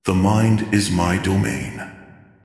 This voice set comes with reverberation echo effect, and the voice content is related to the attack type mind control.